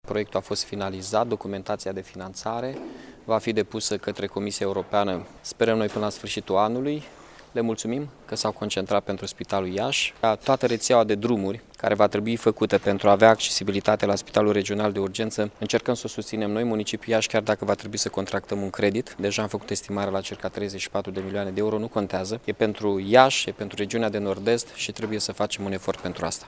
Primarul Mihai Chirica a anunţat, astăzi, într-o conferinţă de presă, că Primăria Municipiului Iaşi va face un credit de 34 de milioane de euro pentru a construi căile de acces către viitorul spital regional de urgenţe.